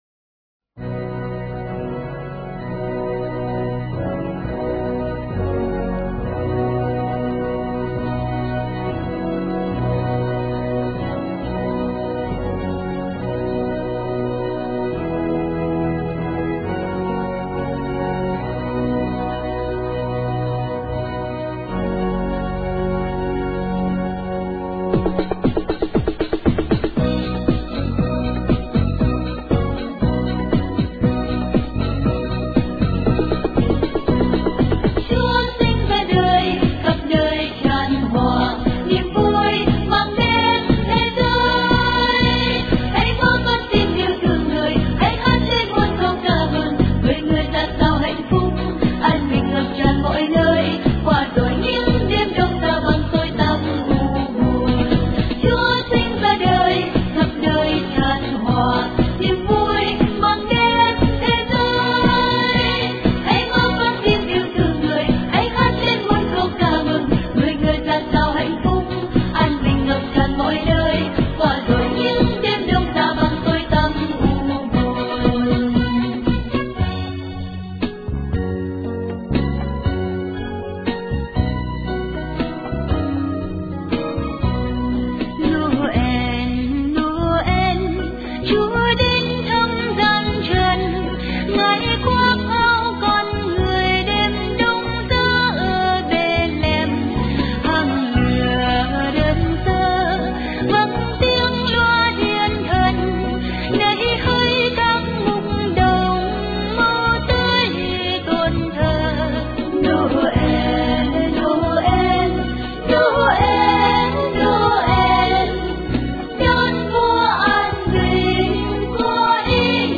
Dòng nhạc : Noel